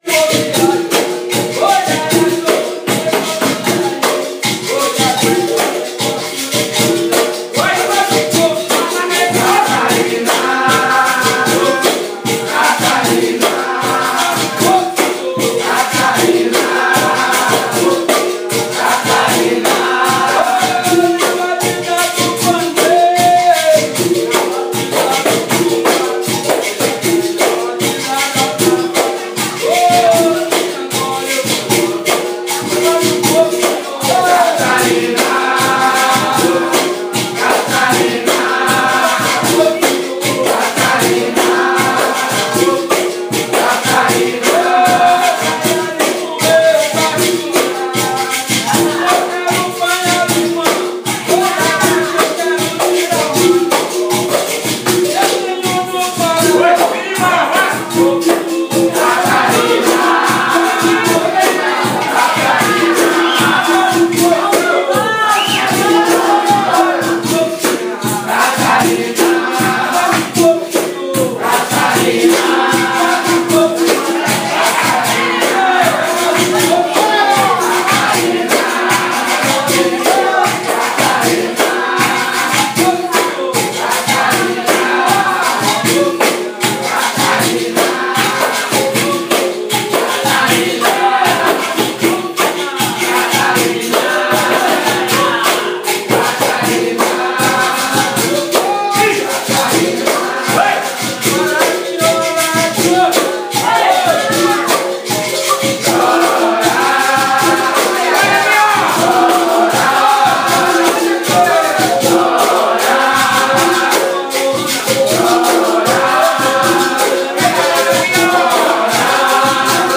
Mind blown by the scene and channeling the spirit of my hero Alan Lomax I pulled out my phone and made my first (and so far only) field recording of the authentic rootsy sounds going down.
with everyone sweating, clapping and joining in the singing and chanting.